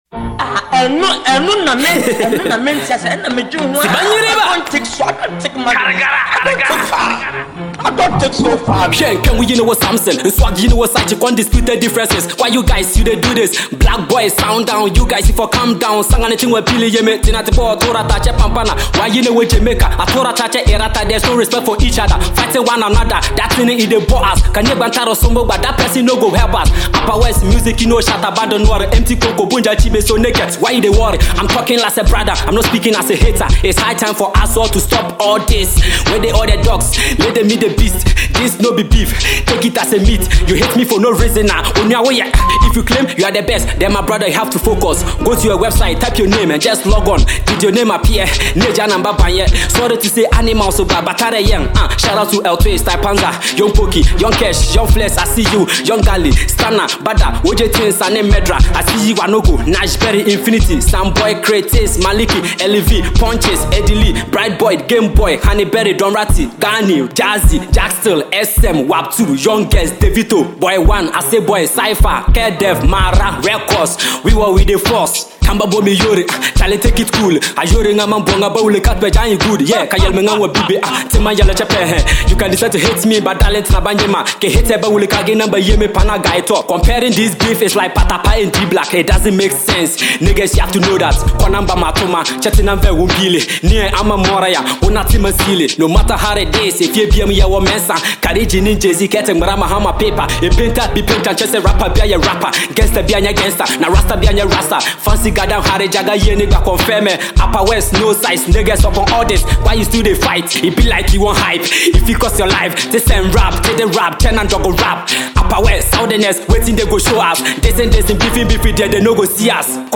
Upper west finest rapper